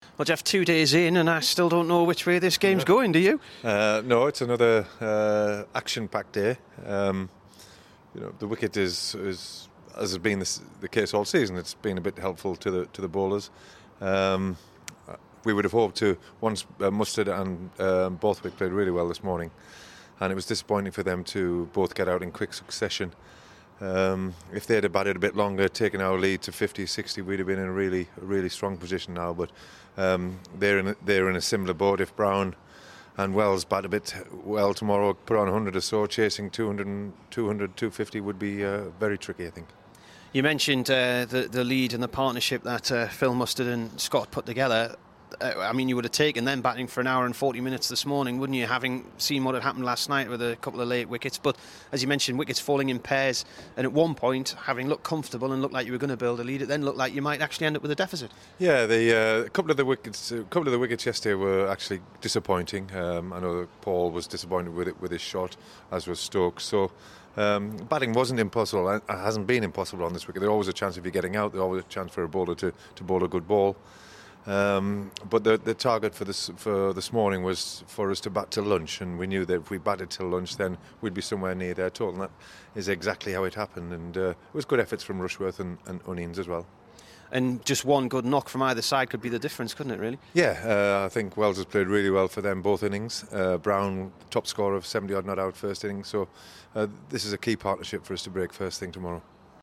Chat after day two of Sussex match.